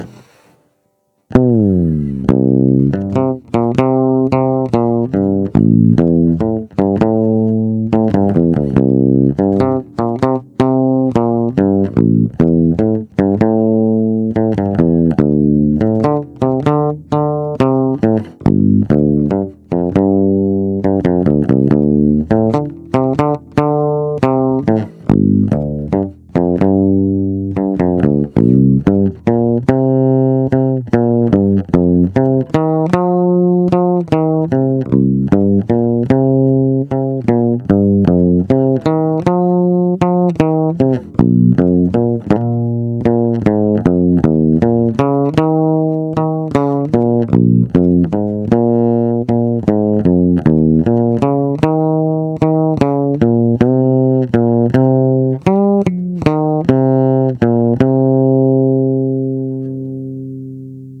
Zvuk je opravdu hutný a i díky piezo snímači dostává akustický, až kontrabasový nádech. Ostatně posuďte sami z nahrávek, které jsou pořízené přes zvukovku do PC, bez úprav.
Kobylkový snímač
Máš moc velkou citlivost na vstupu, takže je signál ořezaný, zkreslený a proto to ve zvuku chrčí.